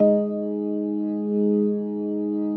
B3LESLIE G#3.wav